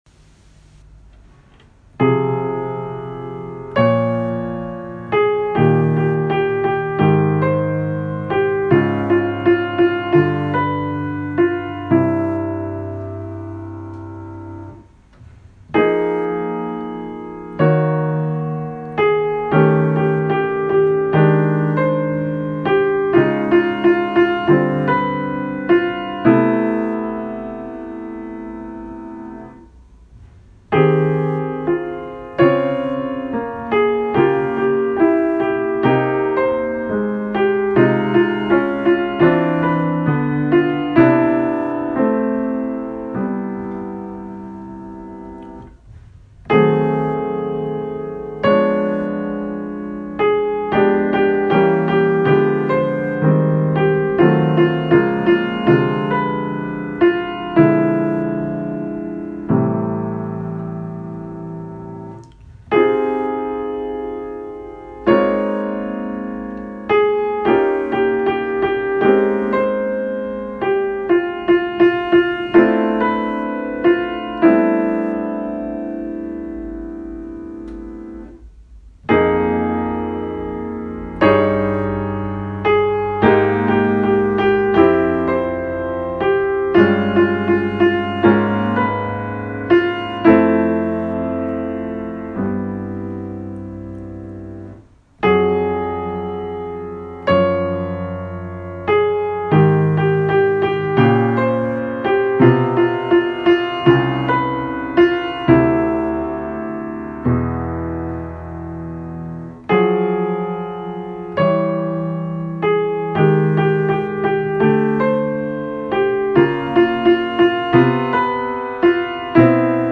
Like Bartók in Mikrokosmos, I gave myself little equations to follow, in this case for making progressively more outrageous chorale harmonizations of Kern’s opening phrase.